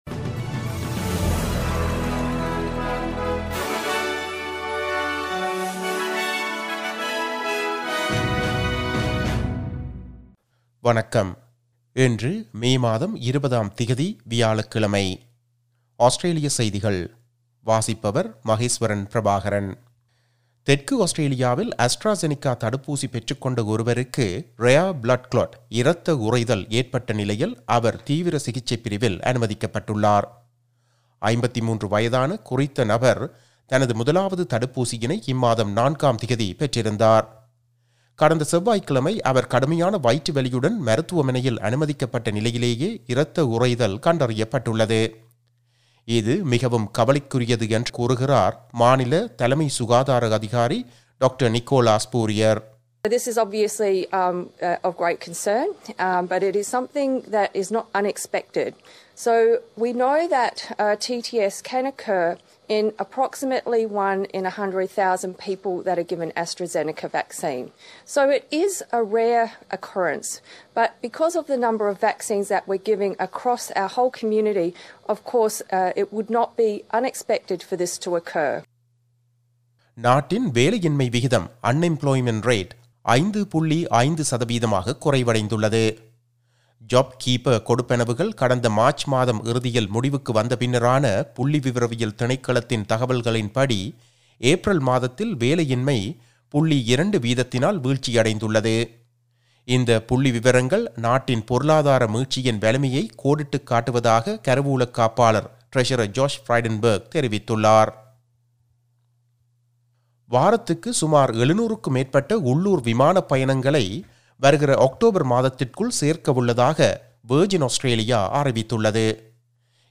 Australian news bulletin for Thursday 20 May 2021.